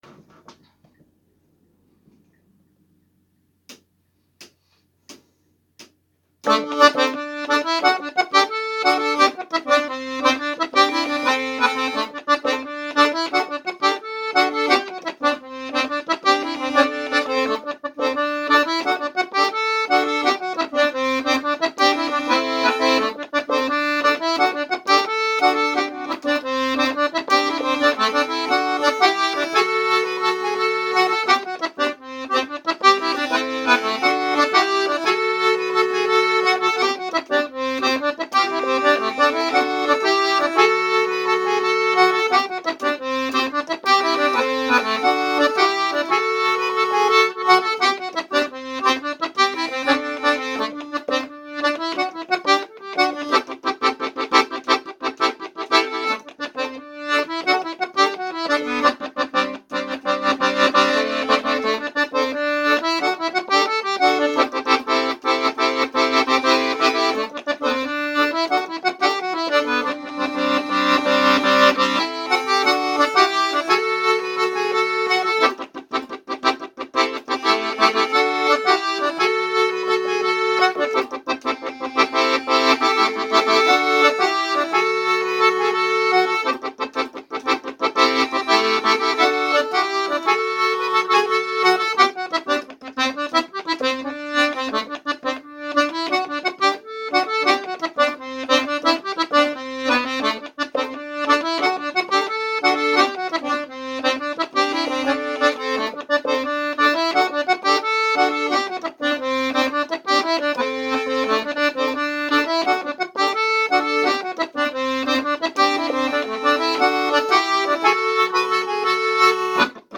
Schottische music in case you want to dance where there is not enough Internet to play the video.